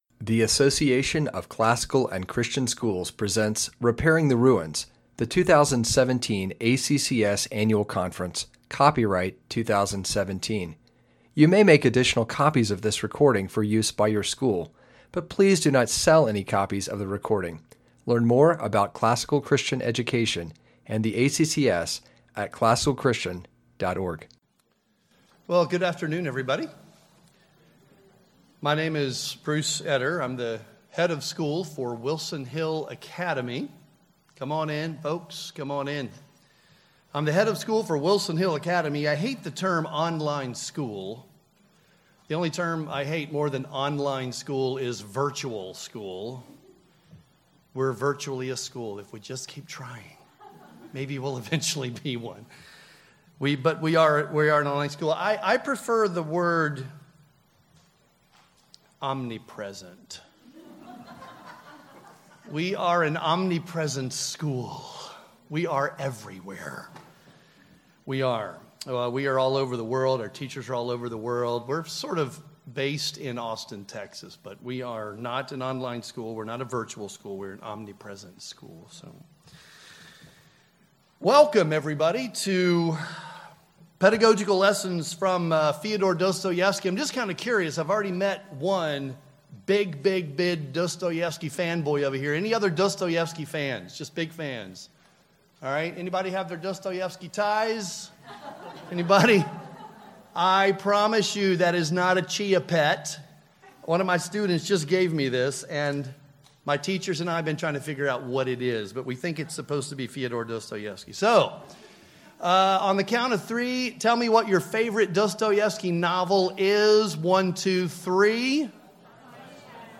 2017 Foundations Talk | 0:55:57 | All Grade Levels, Literature
Jan 9, 2019 | All Grade Levels, Conference Talks, Foundations Talk, Library, Literature, Media_Audio | 0 comments
Additional Materials The Association of Classical & Christian Schools presents Repairing the Ruins, the ACCS annual conference, copyright ACCS.